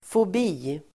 Ladda ner uttalet
fobi substantiv, phobia , fright , horror Uttal: [fob'i:] Böjningar: fobien, fobier Synonymer: extremfruktan, rädsla, skräck Definition: tvångsföreställning; skräck Sammansättningar: hissfobi (phobia about lifts)